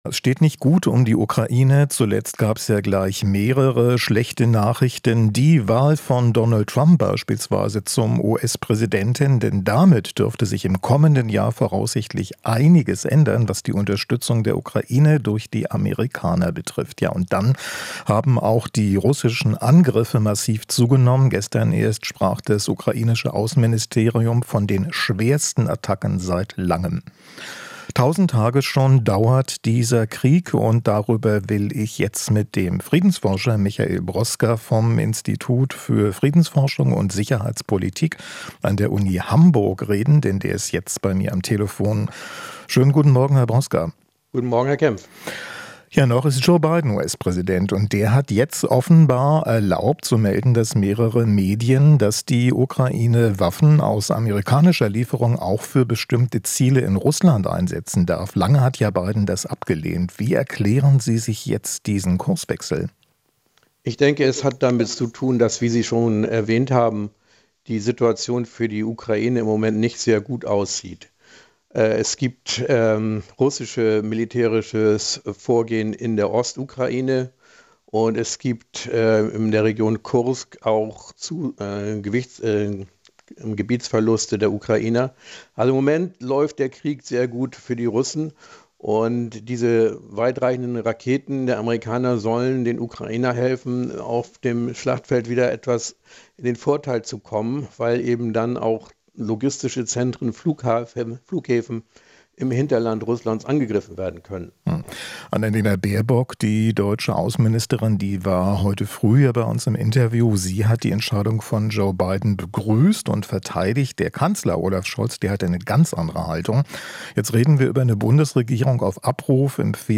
Interview - Ukraine-Unterstützung: Experte erwartet neue Debatte über Taurus